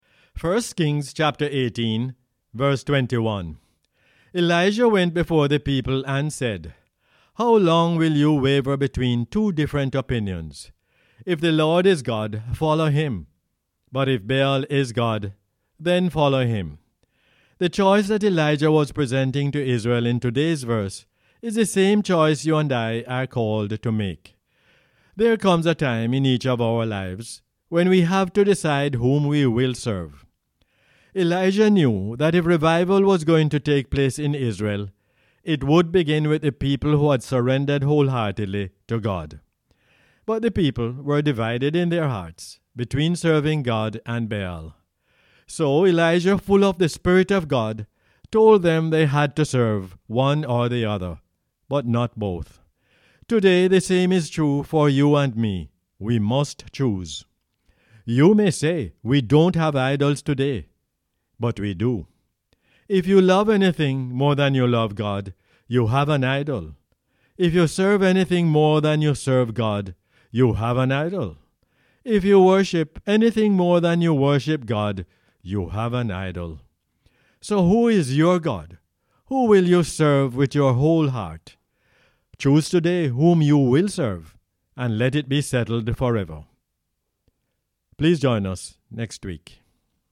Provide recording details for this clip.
1 Kings 18:21 is the "Word For Jamaica" as aired on the radio on 1 October 2021.